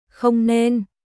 Không nênShouldn’t〜しないほうがいいコン ネン